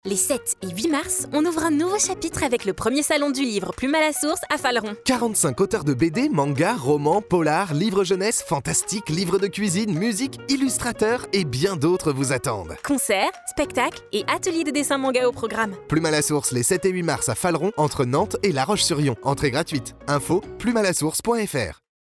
Spot radio RCA LA RADIO
On a mis le paquet avec RCA Radio pour vous proposer quelque chose d’unique : un spot qui ne se contente pas de passer de la musique, mais qui raconte une histoire, qui vous embarque, qui vous titille… bref, qui vous donne envie d’écouter jusqu’à la dernière seconde 👂✨